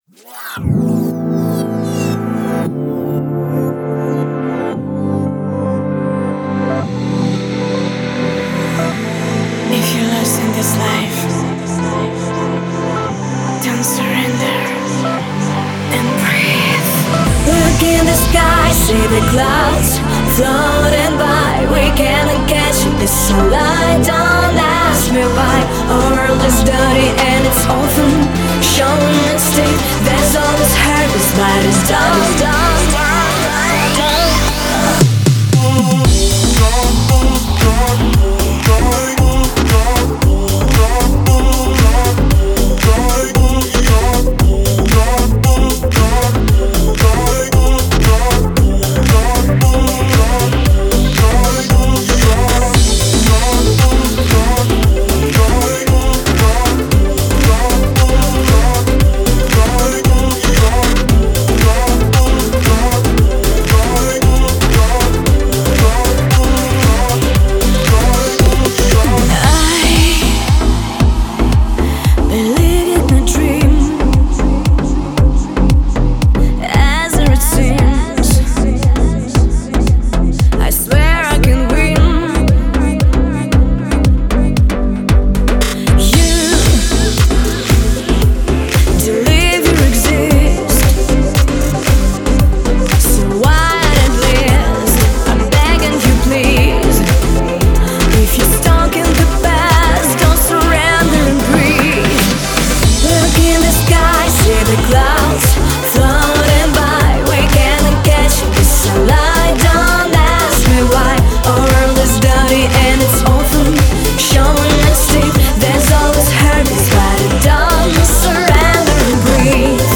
"Breathe" (Dance-Pop